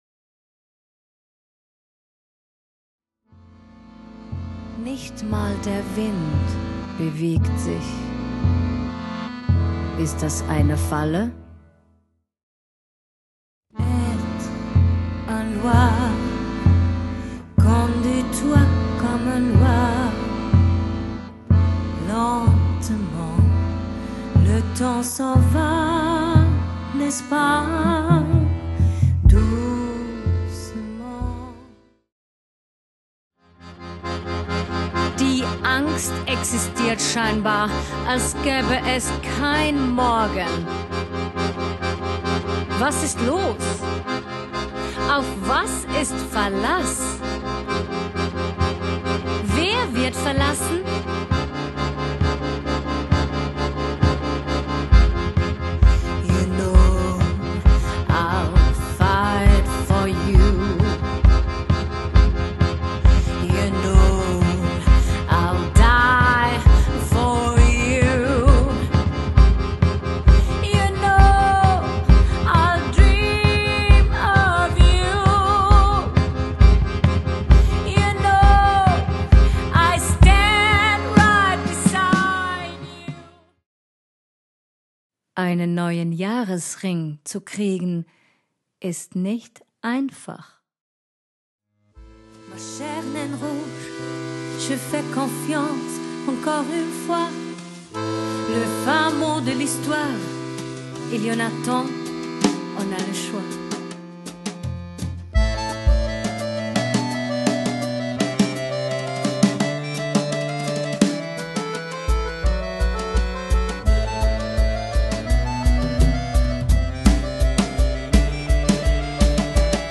STIMME
AKKORDEON
SCHLAGZEUG